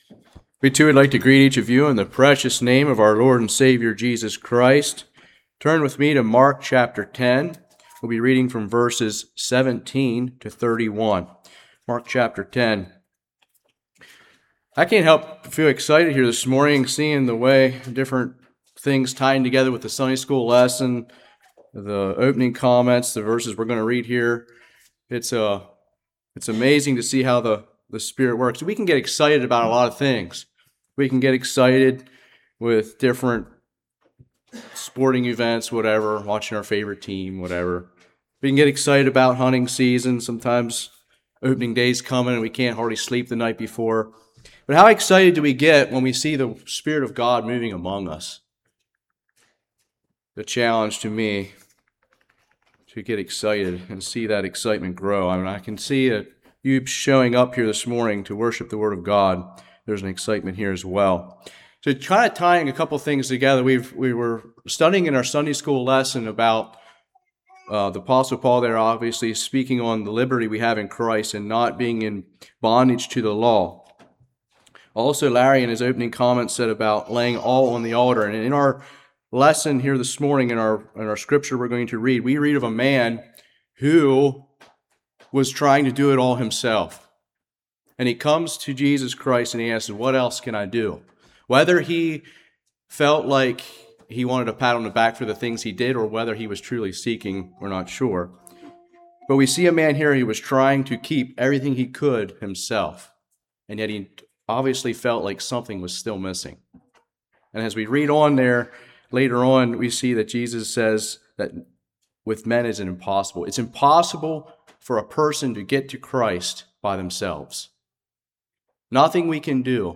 Passage: Mark 10:17-31 Service Type: Morning